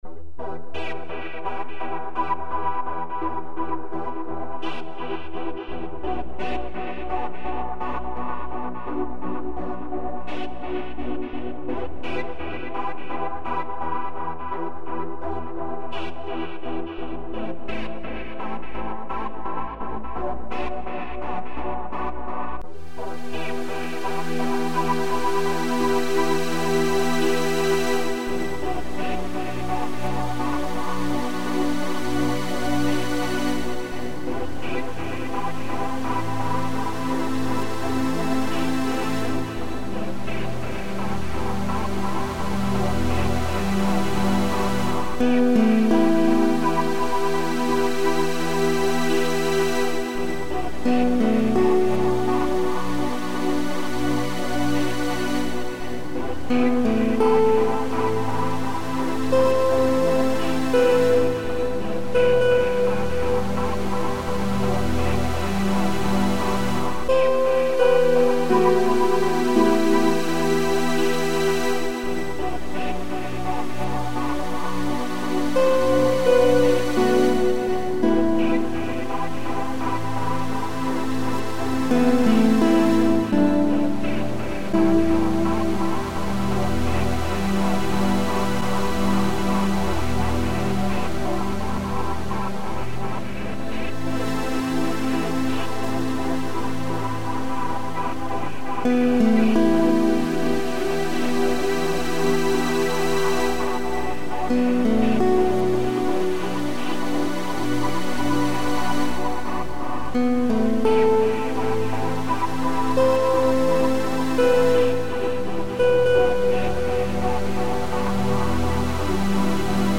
Peace. Tranquility. Drifting along a sunny beachside.